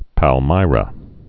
(păl-mīrə)